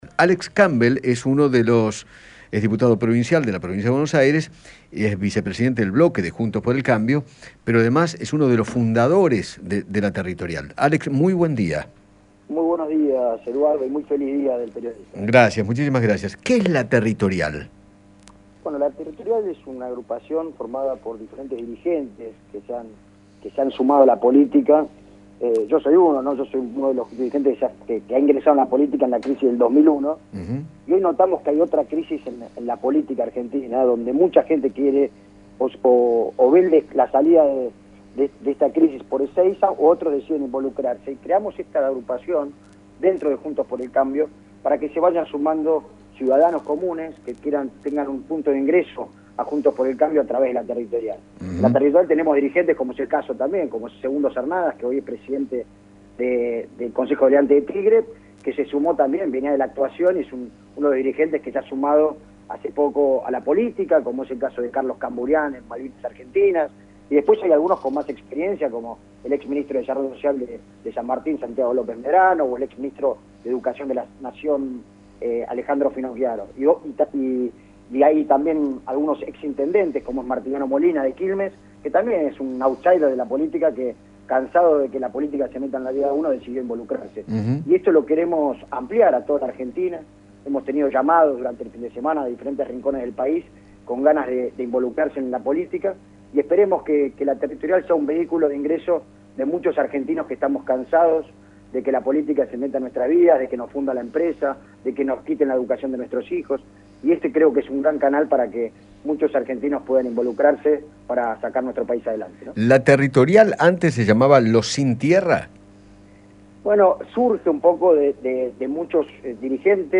El diputado Alex Campbell habló con Eduardo Feinmann acerca de La Territorial, la nueva apuesta de Juntos por el Cambio para “que se vayan sumando ciudadanos comunes que tengan un punto de ingreso a través de la territorial”.